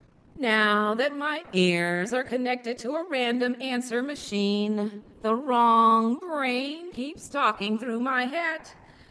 When we flip the intonation pattern, so that each utterance ends on a relatively low pitch, she sounds more conventional, a poetic authority declaring observations, confident and closed off.